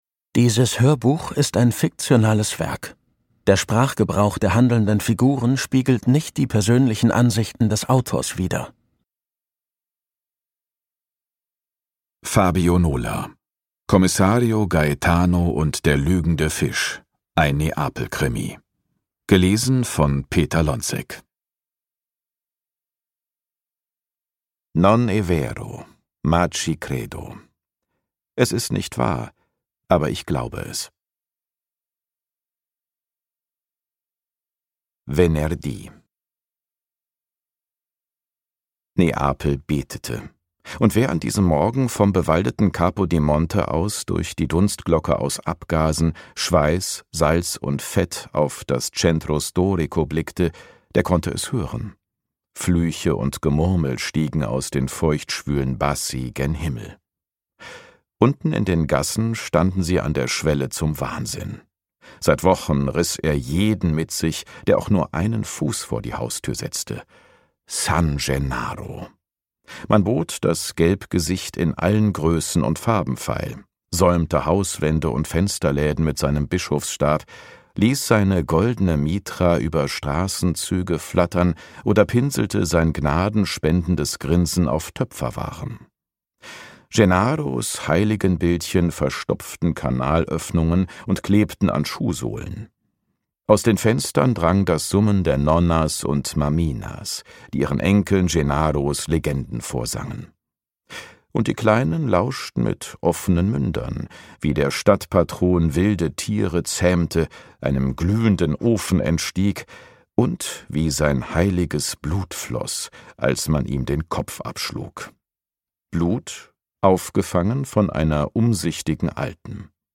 Ungekürzte Lesung
mit kraftvoller Präsenz und emotionaler Intensität dargeboten